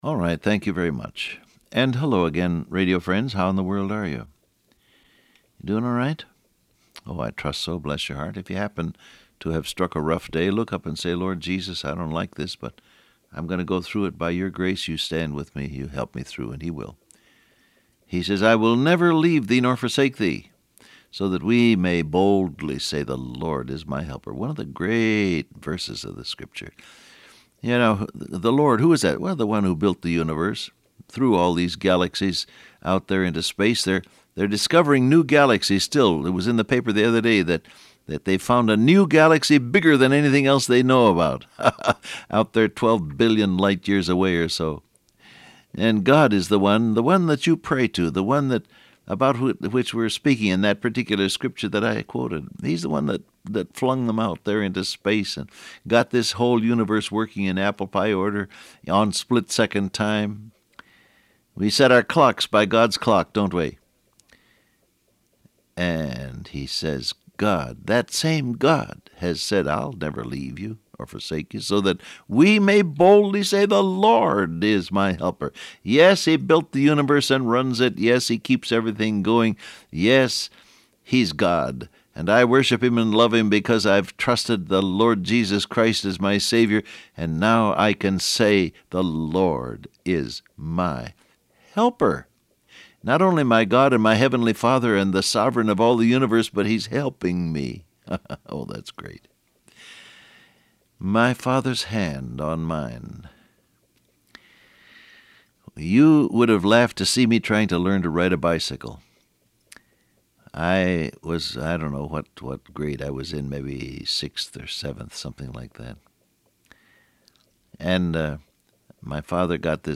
Download Audio Print Broadcast #6826 Scripture: John 3:16 Topics: Commit , The Lord My Helper , My Fathers Hand , His Love Transcript Facebook Twitter WhatsApp Alright, thank you very much.